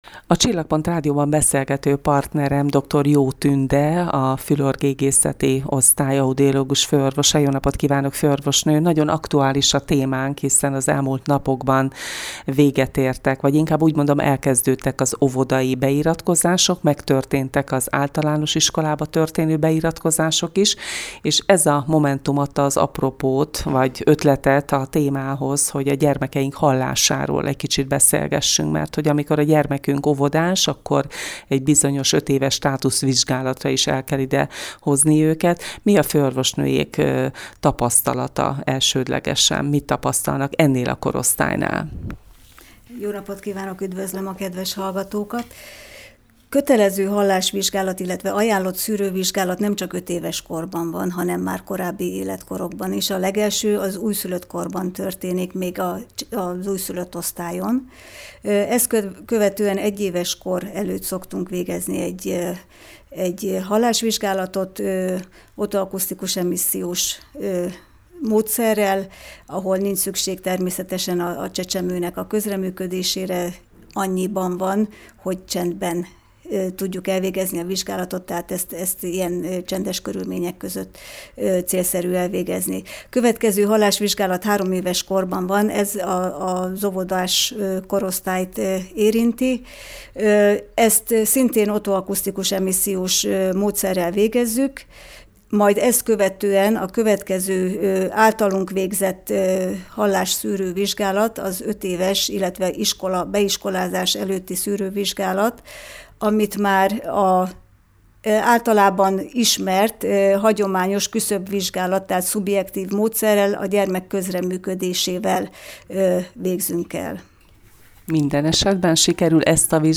Az óvodai és iskolai beiratkozások adták az apropót ahhoz a beszélgetéshez, amelyben gyermekeink hallásáról és annak szűrési lehetőségeiről kérdeztük a szakembert.